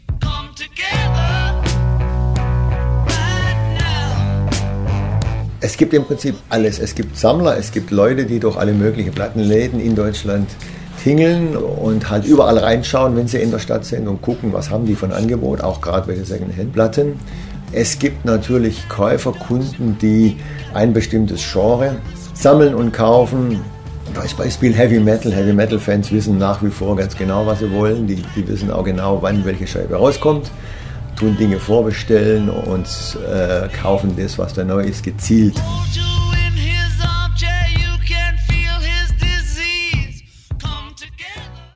Wir begegnen Menschen, die der Platte treu geblieben sind.